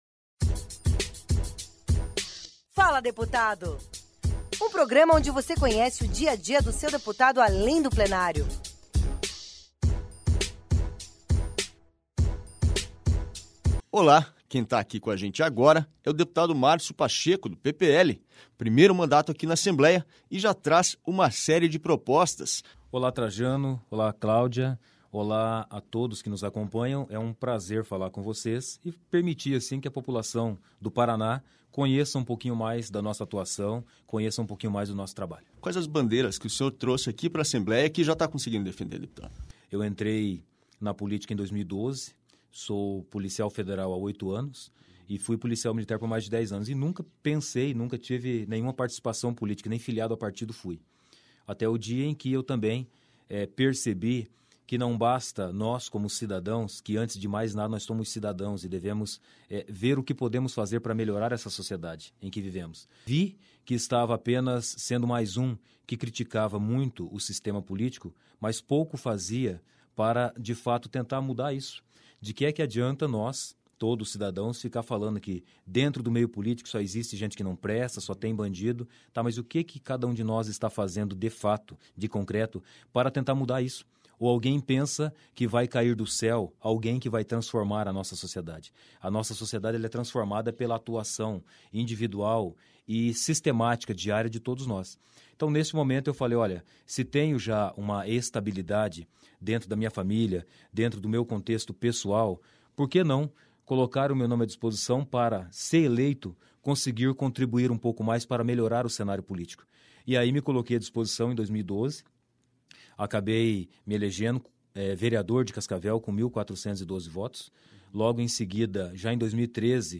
Conheça mais sobre Márcio Pacheco em uma entrevista inspiradora ao Fala deputado